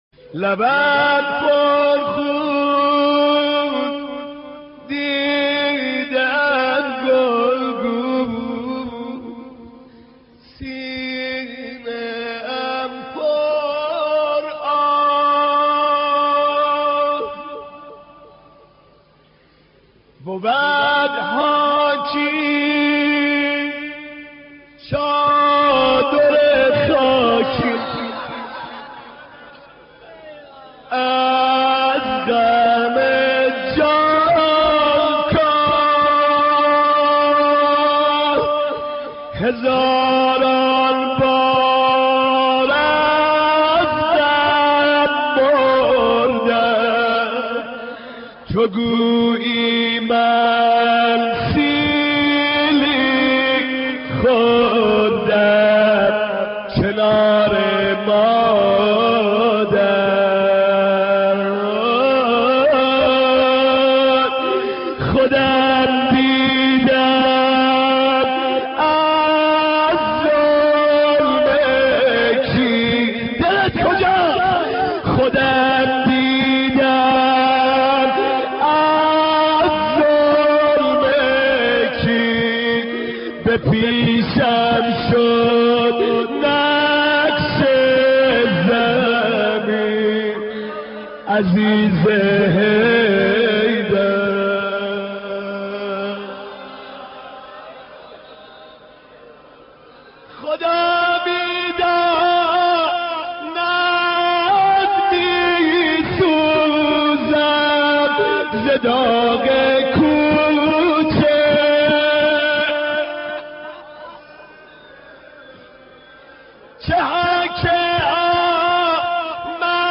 مداحی و نوحه
مجلس نوحه خوانی به مناسبت شهادت حضرت فاطمه زهرا (س)